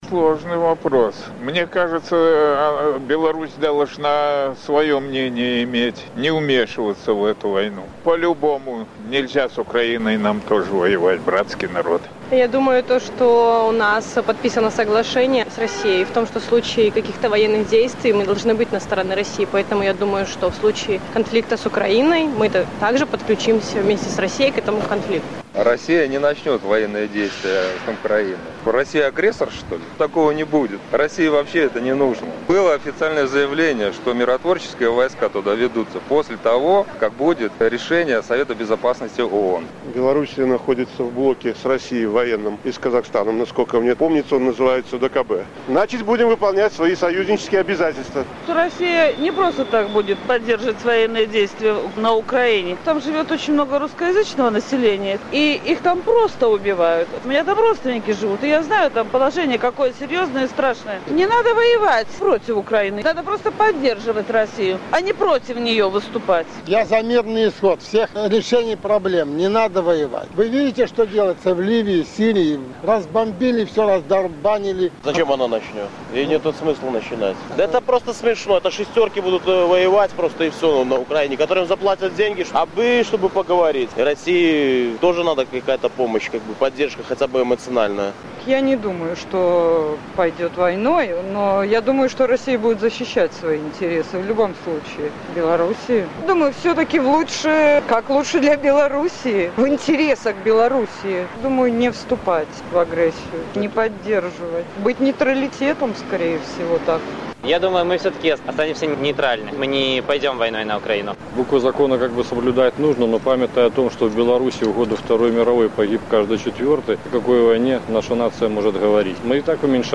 На гэтае пытаньне адказваюць жыхары Гомеля.